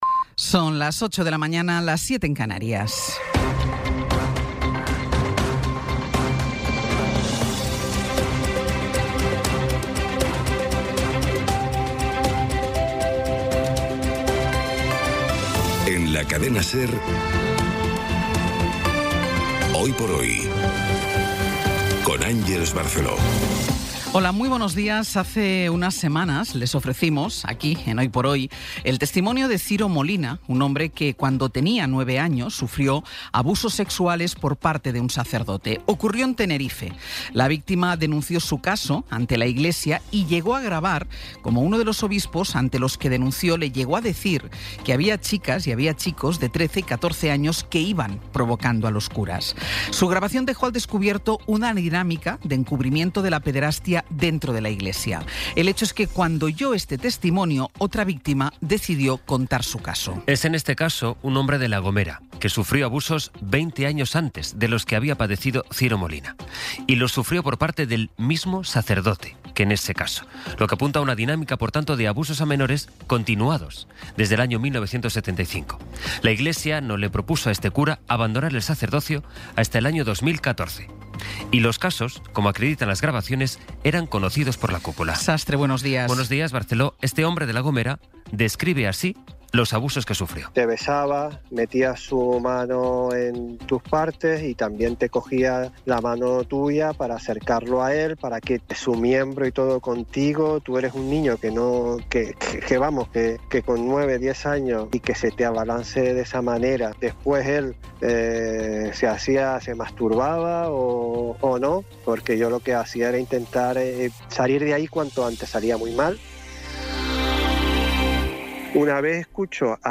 Las noticias de las 08:00 20:12 SER Podcast Resumen informativo con las noticias más destacadas del 21 de abril de 2026 a las ocho de la mañana.